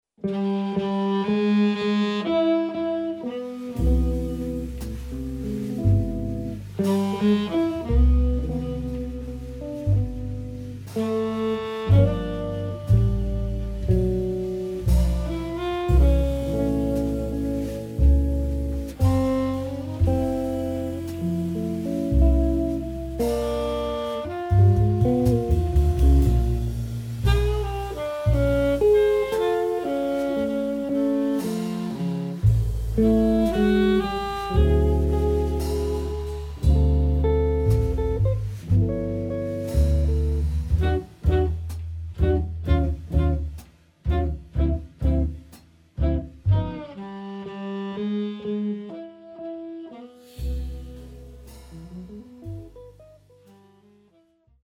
guitar
sax, flute
bass
drums